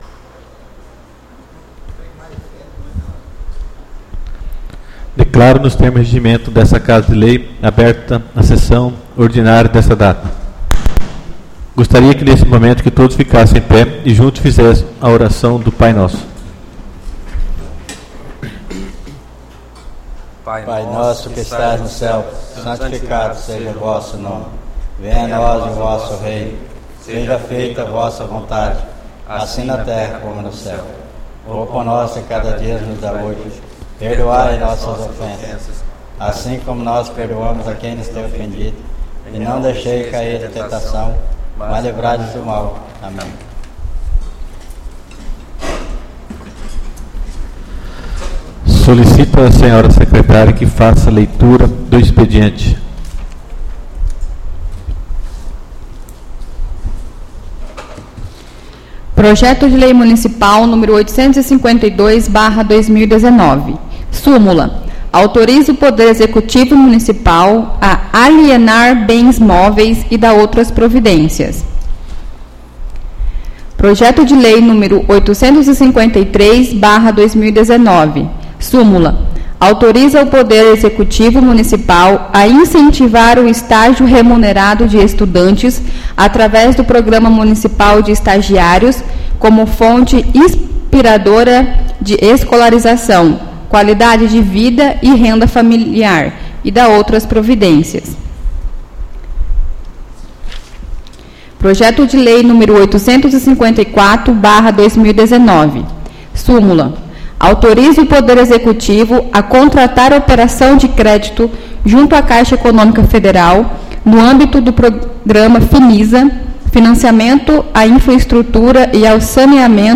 Áudio da Sessão Ordinária 04/06/2019